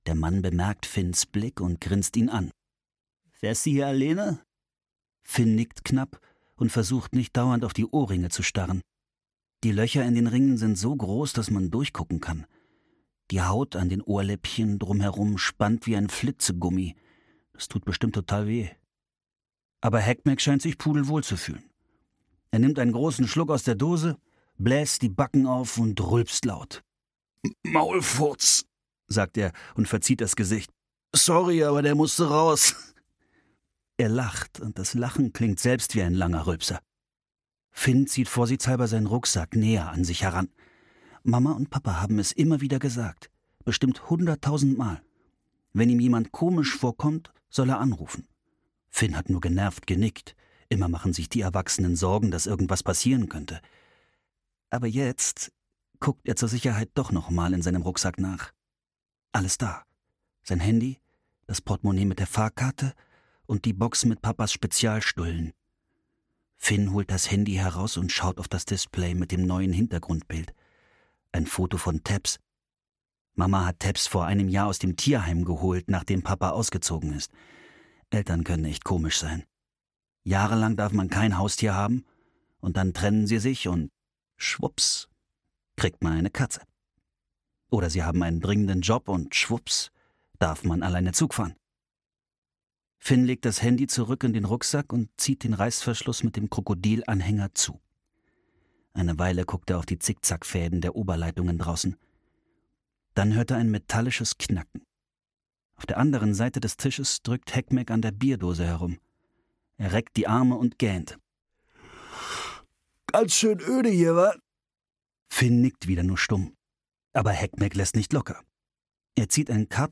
Kannawoniwasein - Manchmal muss man einfach verduften - Martin Muser - Hörbuch